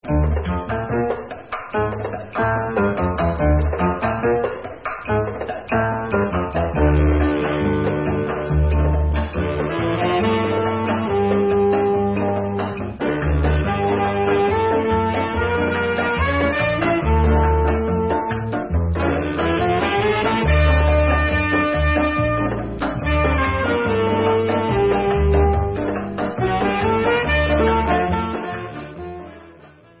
צ'ה צ'ה צ'ה
Cha-Cha-Cha-PI-.mp3